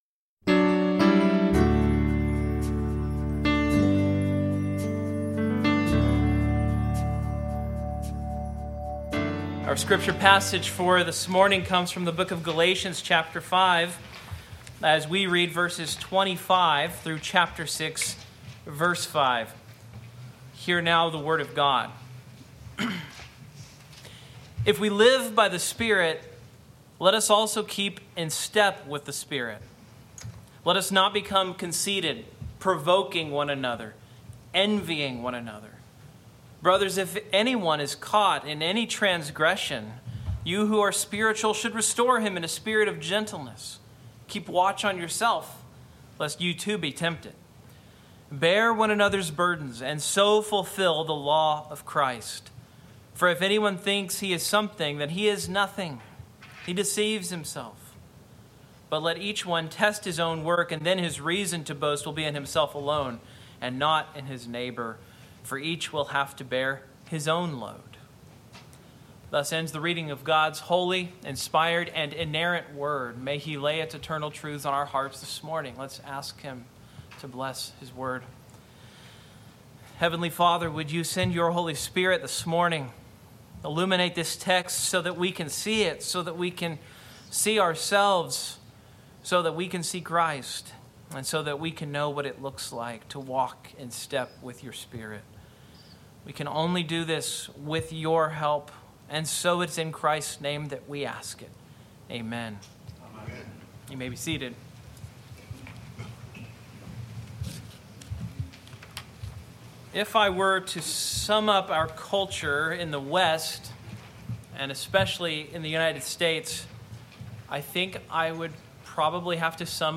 Galatians 5:25-6:5 Service Type: Morning Outline